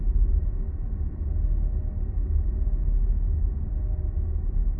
shell_hum.wav